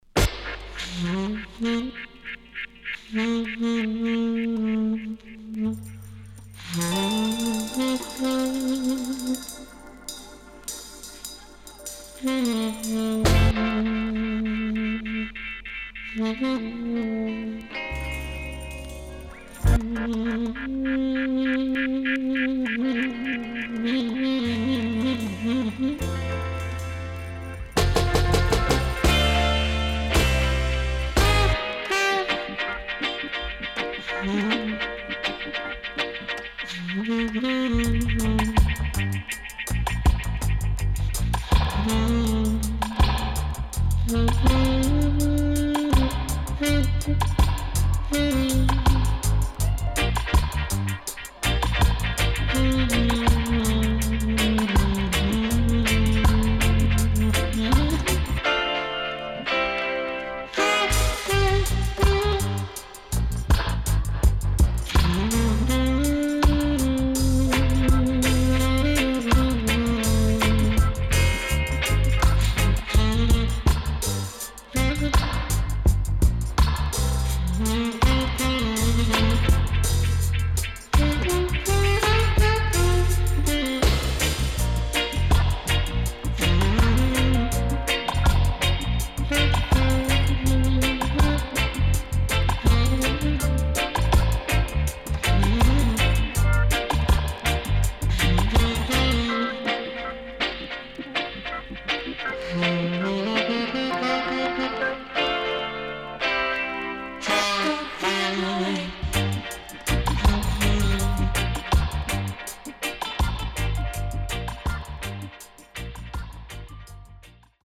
【12inch】-Color Vinyl
SIDE A:盤質は良好です。